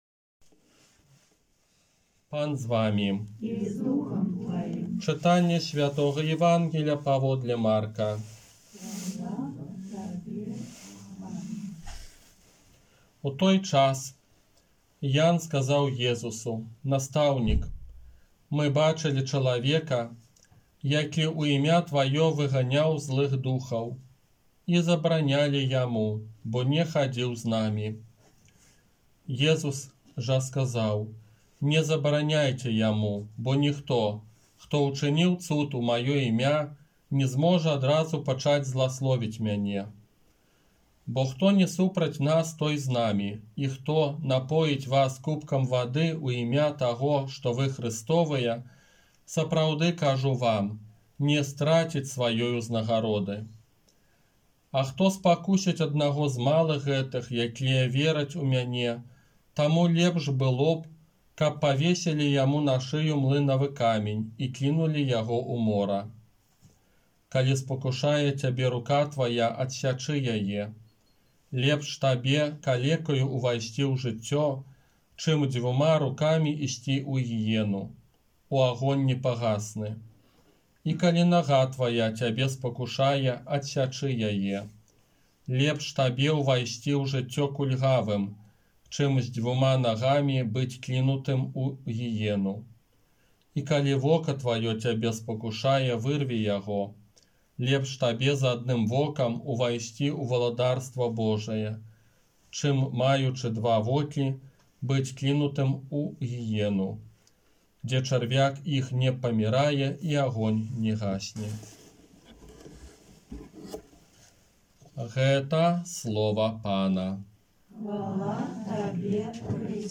ОРША - ПАРАФІЯ СВЯТОГА ЯЗЭПА
Казанне на дваццаць шостую звычайную нядзелю